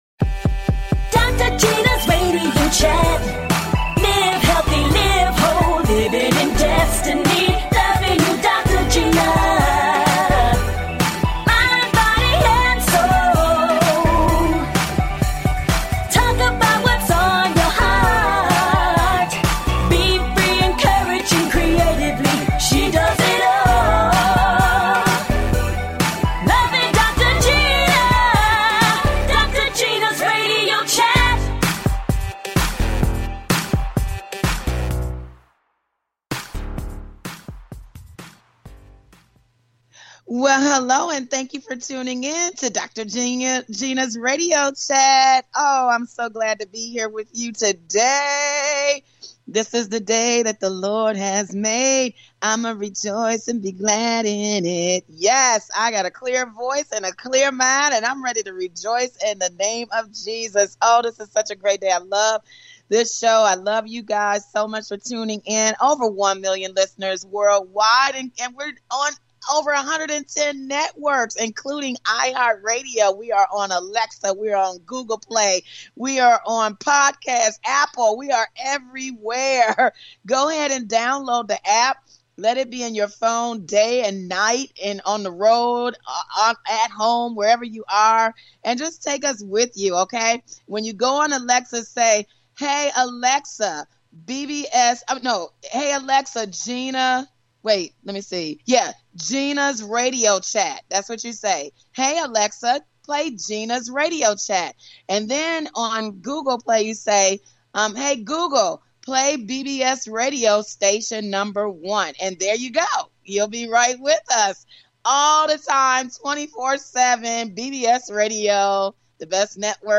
And full of laughter!
It's a platform of transparency. A talk show of encouragement.